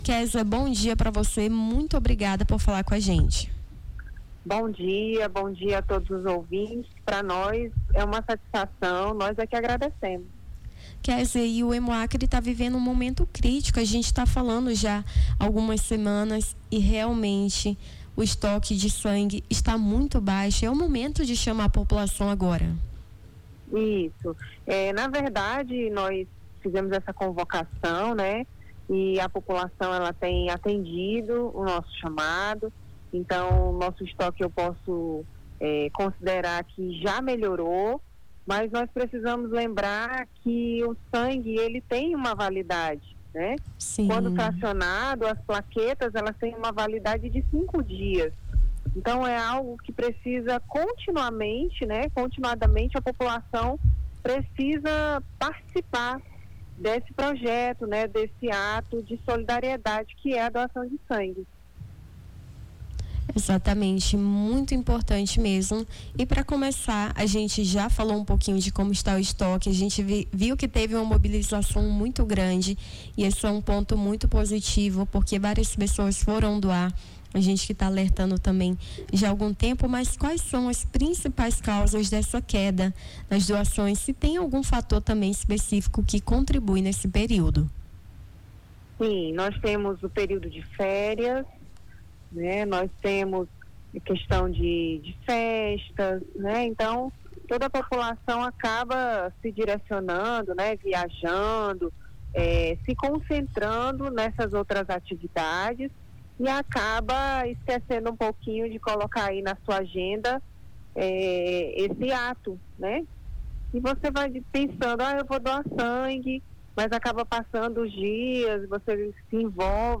Nome do Artista - CENSURA - ENTREVISTA (ESTOQUE CRITICO HEMOACRE) 11-08-25.mp3